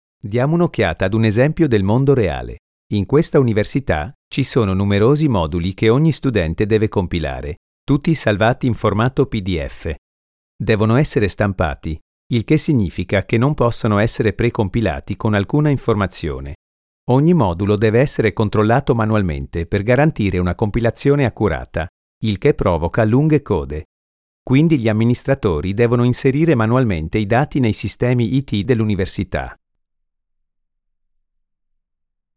Male
Assured, Authoritative, Character, Confident, Engaging, Friendly, Gravitas, Natural, Reassuring, Smooth, Warm, Versatile
Microphone: Neumann TLM 103